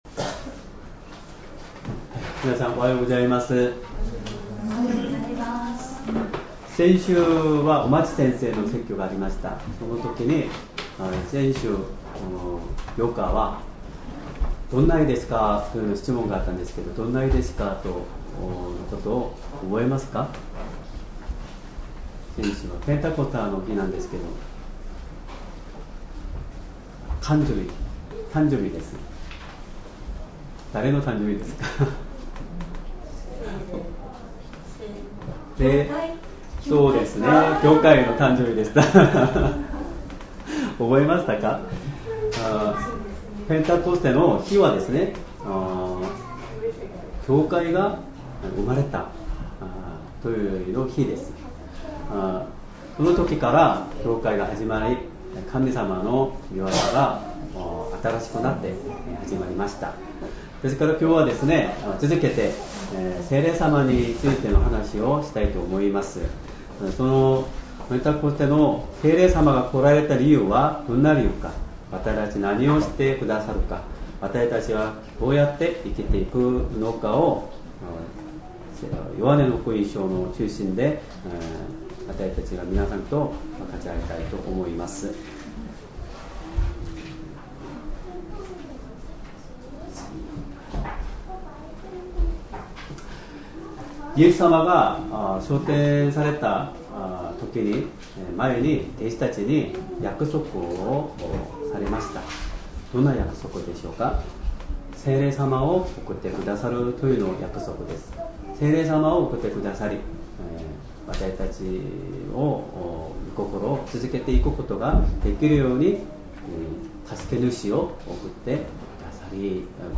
Sermon
2025年6月15日 主日礼拝 説教 「我らと共におられる助け主 」 聖書 ヨハネの福音書 14章16-20 14:16 そしてわたしが父にお願いすると、父はもう一人の助け主をお与えくださり、その助け主がいつまでも、あなたがたとともにいるようにしてくださいます。